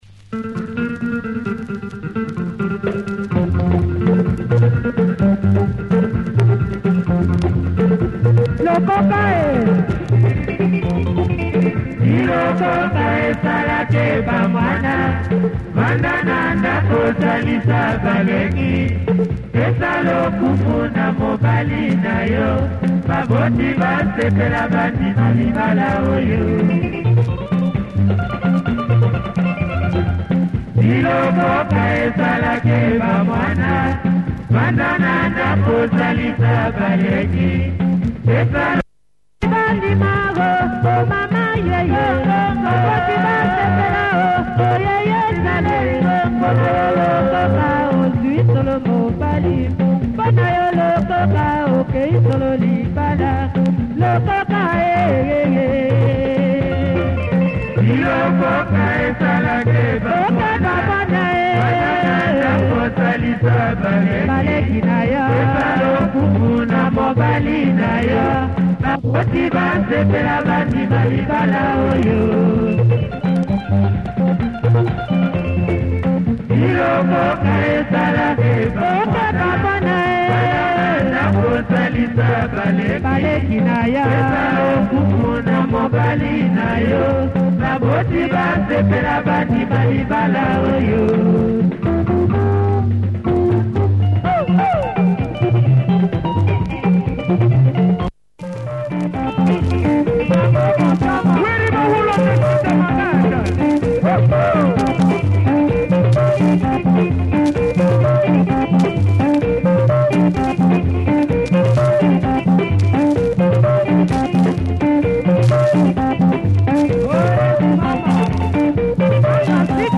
Low-Fi lingala from Congo, cool beat on this one.